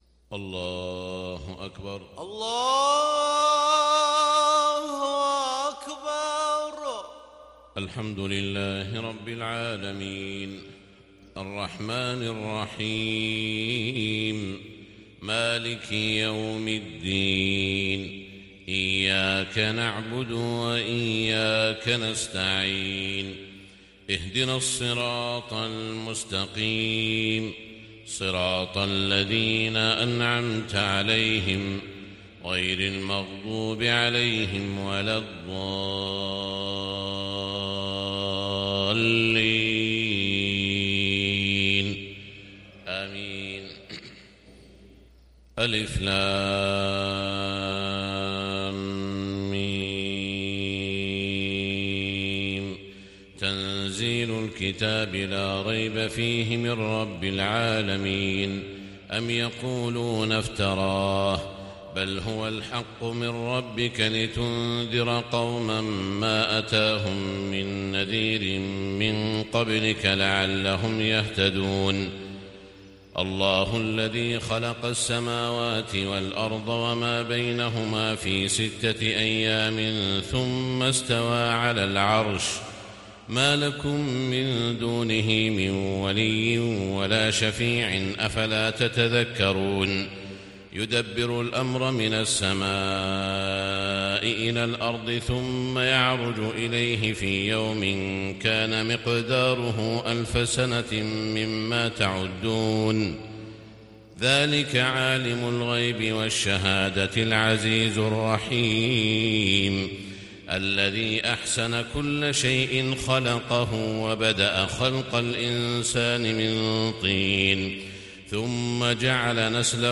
صلاة الفجر للشيخ سعود الشريم 29 جمادي الأول 1441 هـ
تِلَاوَات الْحَرَمَيْن .